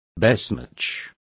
Complete with pronunciation of the translation of besmirch.